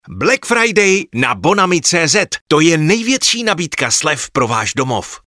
nabízím profesionální, příjemný mužský hlas ošlehaný téměř 30 lety zkušeností u mikrofonů v rádiích i studiích.
Pracuji ve svém nahrávacím studiu nebo po dohodě kdekoliv jinde.